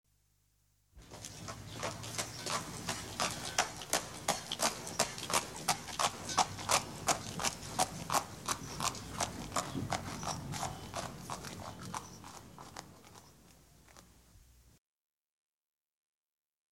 Pianeta Gratis - Audio - Animali
cavalli_horses02.mp3